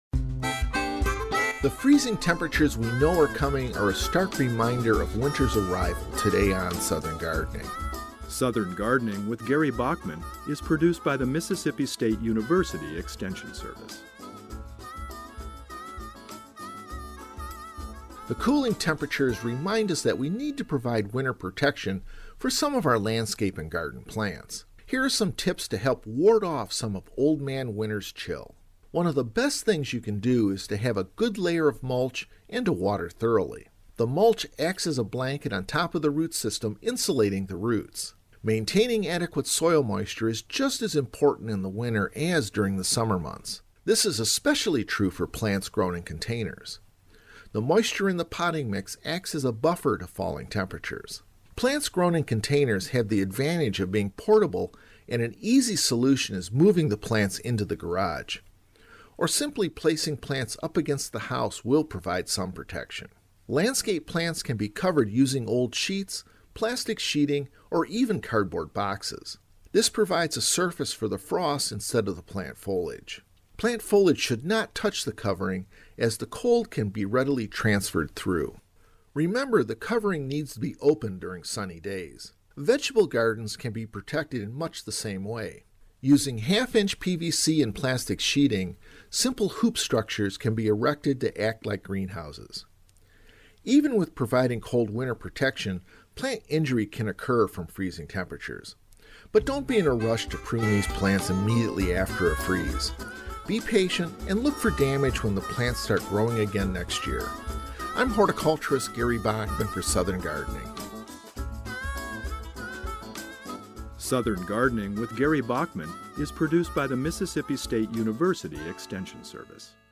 Host